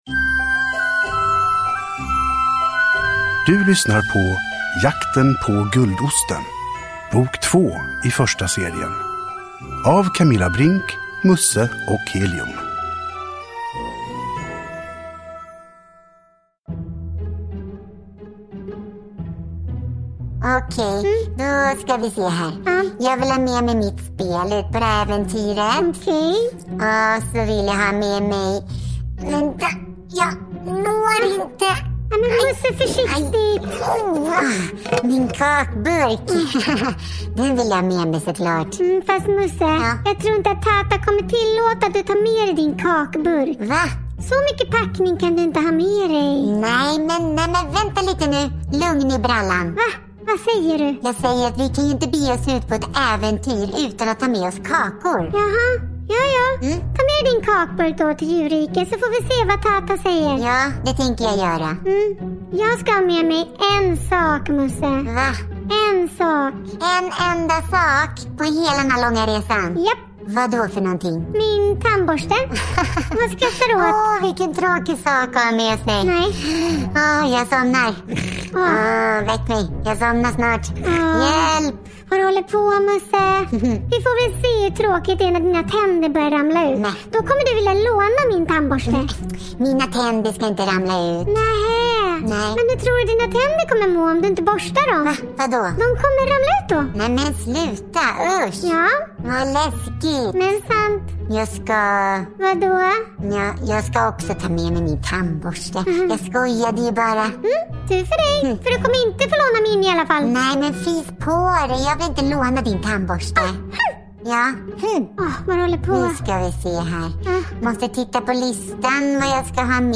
Dramatiserad ljudberättelse!
Uppläsare: Camilla Brinck